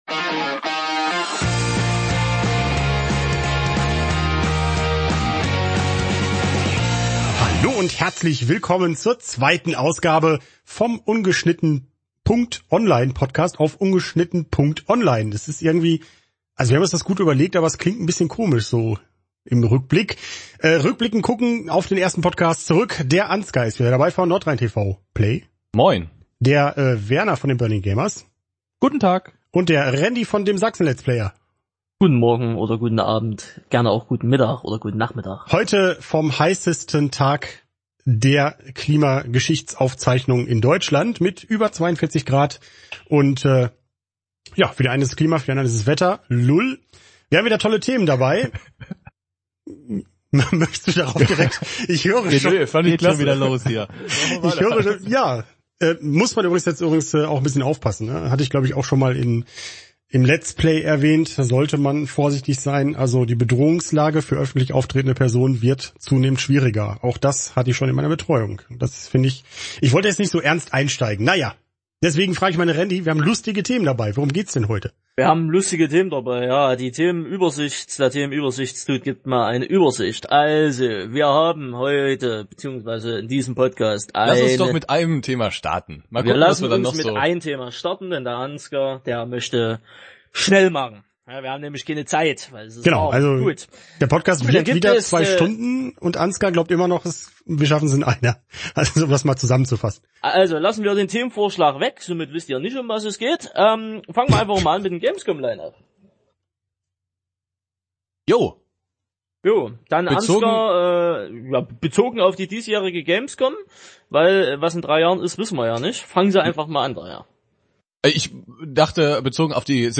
Vier Youtuber – viele Meinungen.